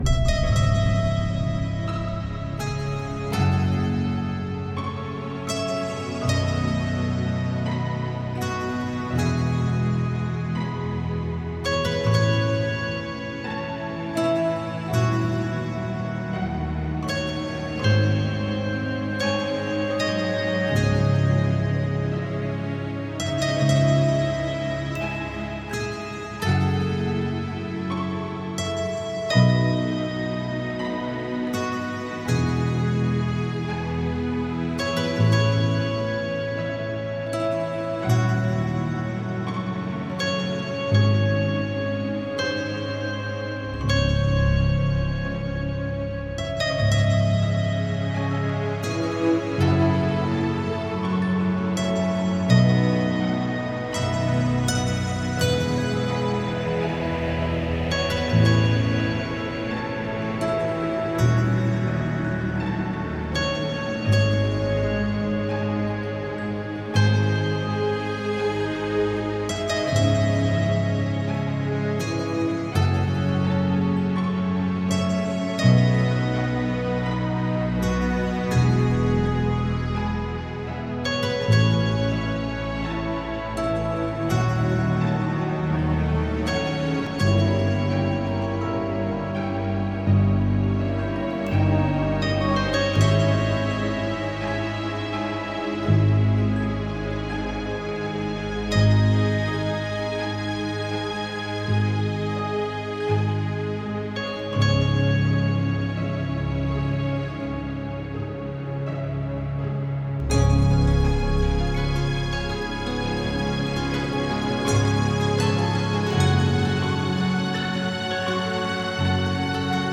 موسیقی بیکلام
موسیقی اینسترومنتال